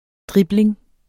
Udtale [ ˈdʁibleŋ ]